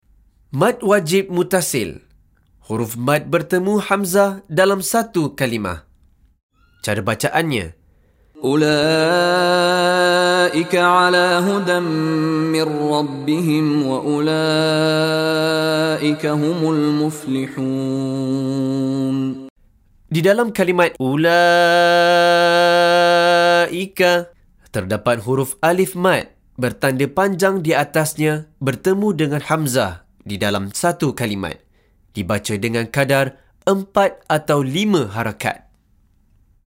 Penerangan Hukum + Contoh Bacaan dari Sheikh Mishary Rashid Al-Afasy